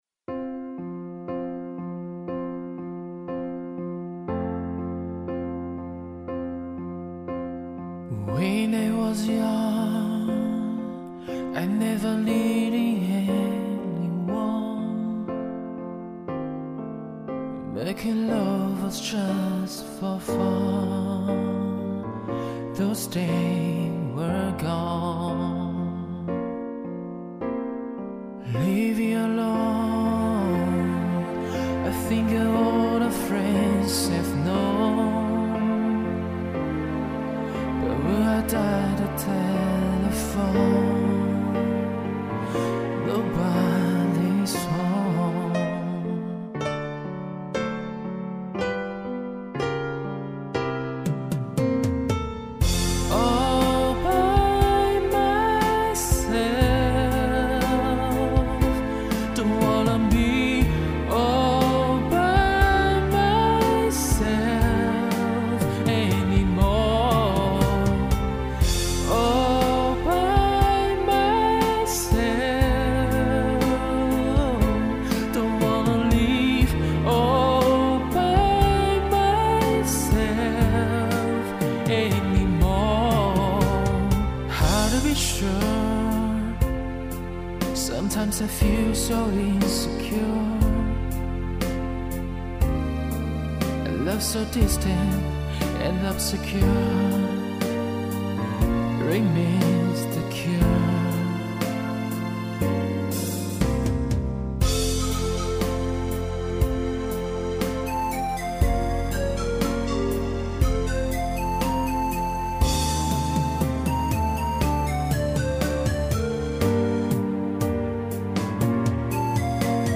好好听，楼主好温柔哦{:5_148:}
好溫柔啊